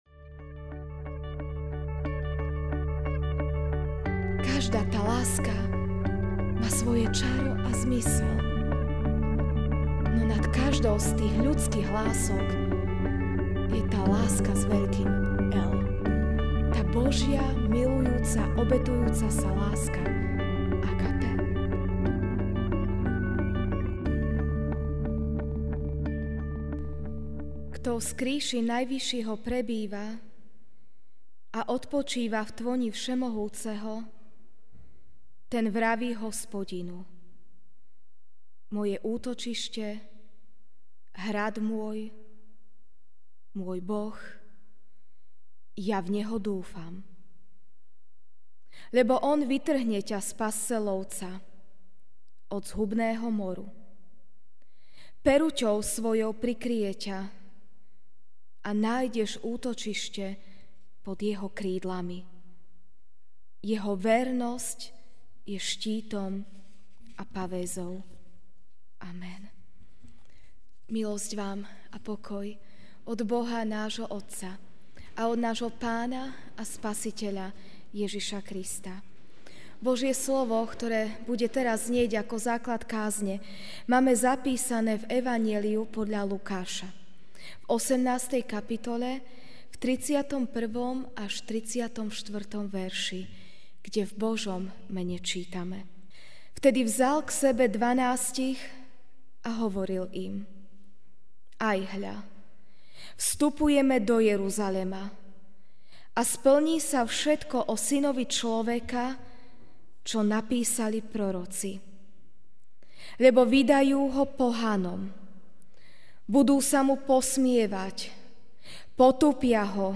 Večerná kázeň: Láska - Eros, Fileo, Agape (L 18,31-34) 'Vtedy vzal k sebe dvanástich a hovoril im: Ajhľa, vstupujeme do Jeruzalema, a splní sa všetko o Synovi človeka, čo napísali proroci.